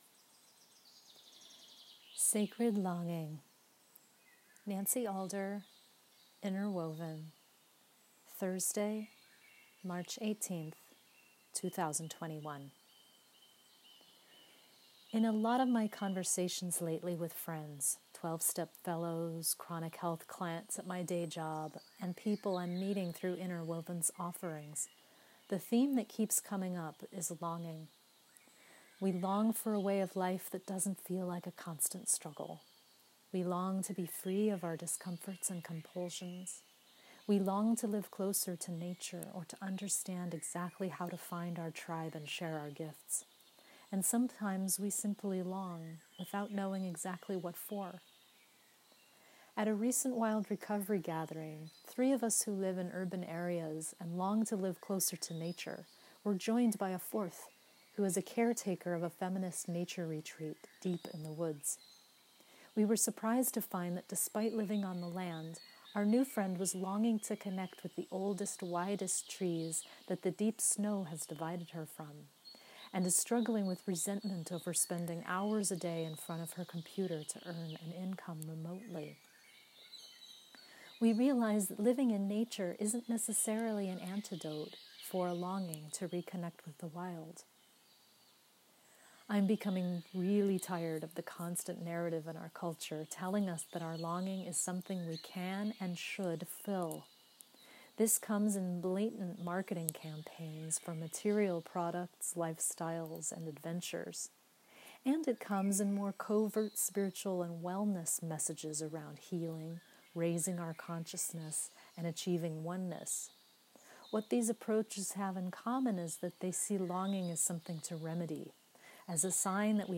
Enjoy this 8-minute read – or have me read it to you – via the recording at the beginning of my webpost.